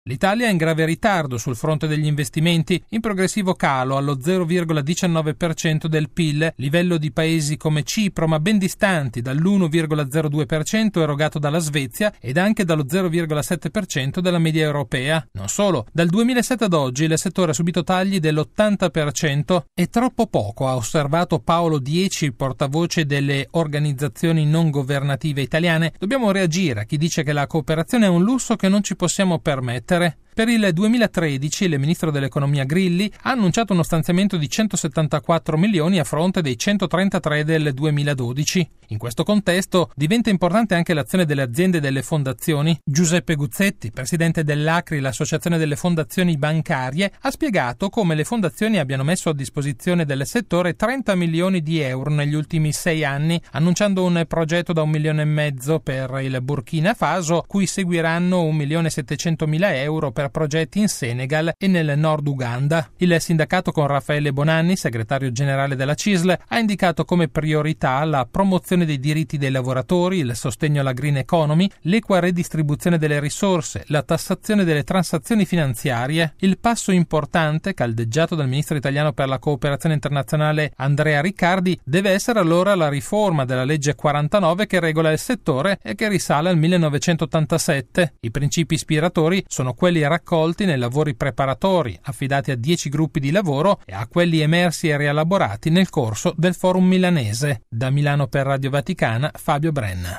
Radiogiornale del 02/10/2012 - Radio Vaticana